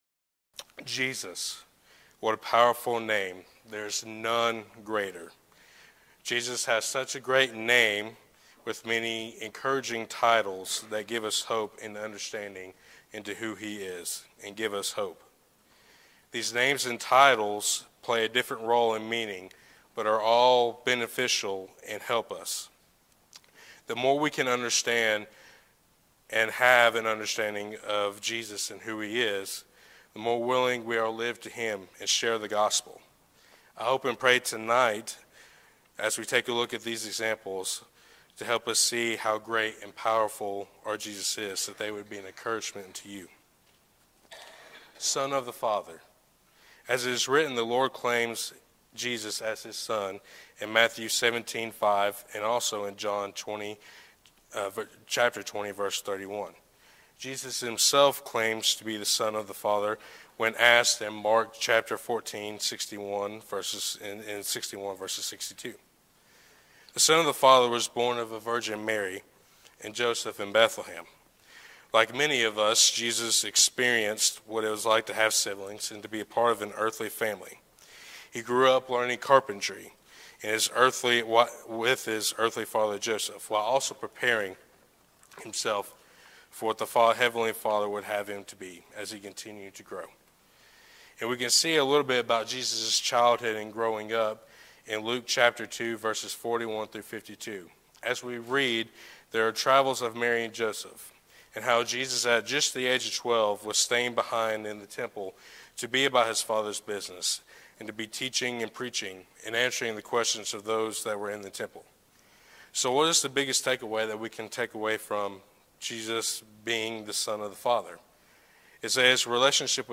Series: Eastside Sermons
Service Type: Sunday Evening